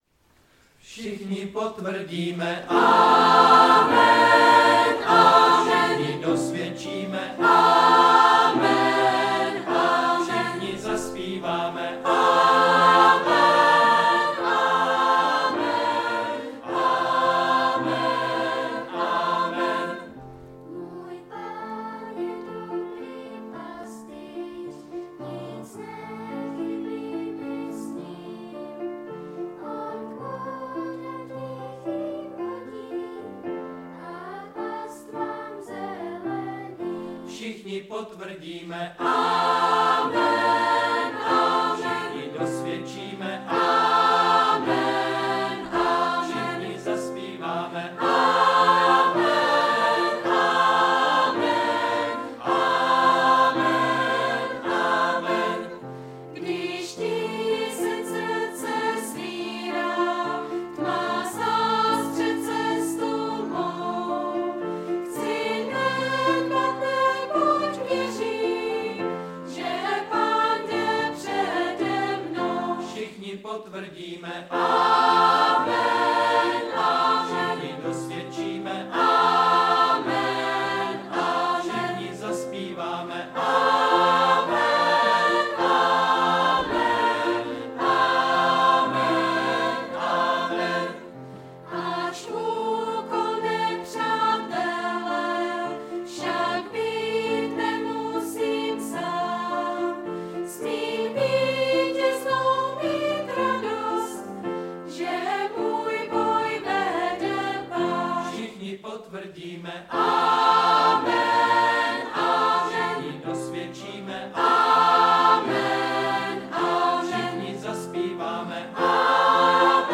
• Řečník: Smíšený sbor
nahrávka s dětmi